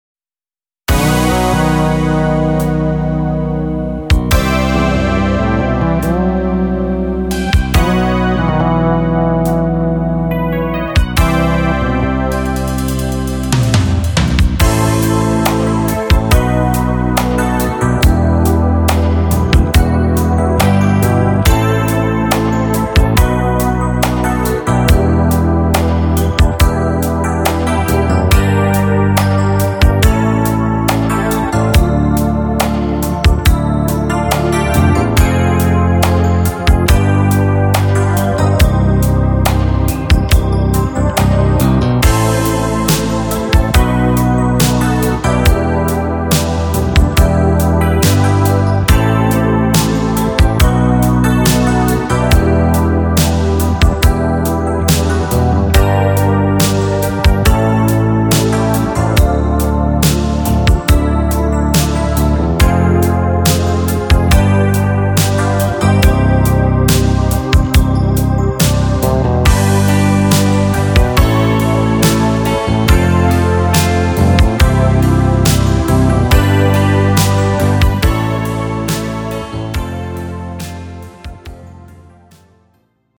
podkład dla wokalistów